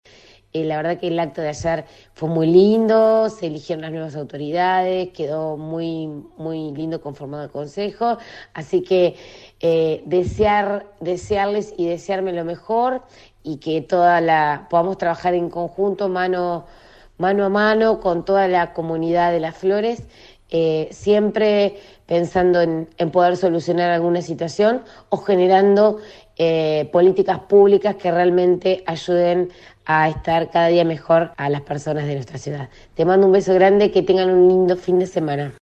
(incluye audios) Los flamantes ediles dejaron este viernes en la 91.5 su reflexión por lo vivido en el marco de la sesión preparatoria que tuvo lugar en la tarde del jueves en el salón «Dr. Oscar Alende» del HCD.